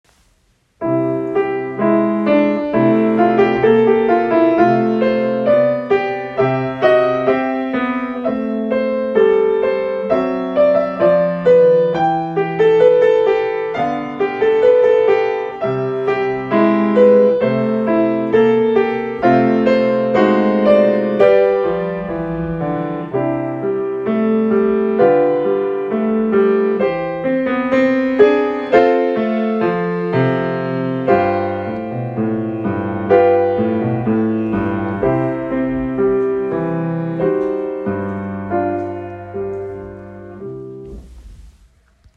Pour piano DEGRE FIN DE CYCLE 1 Durée